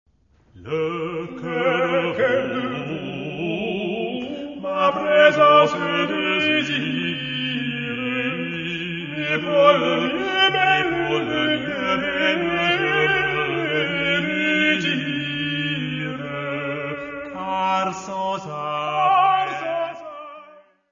Mary's music : Songs and dances from the time of Mary Queen of Scots
Área:  Música Clássica